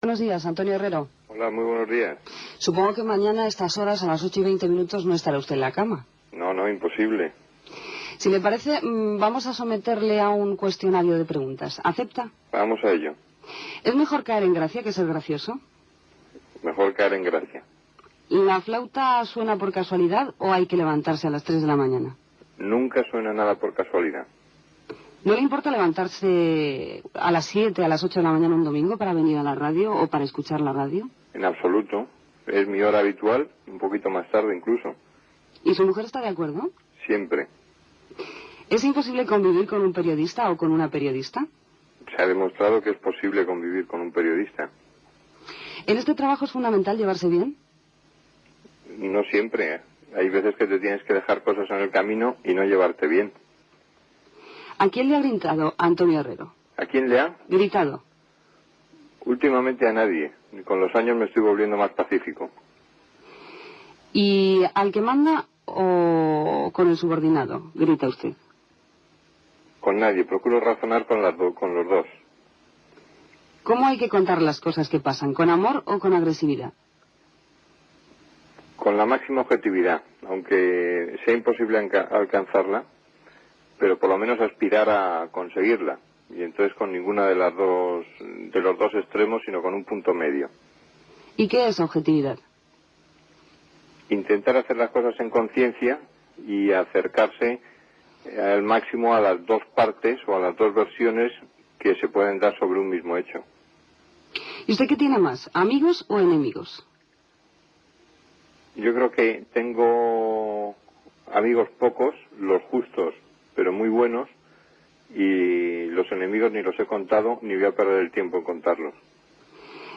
Entrevista a Antonio Herrero el dia abans que comenci a presentar "Primera hora" a la Cadena COPE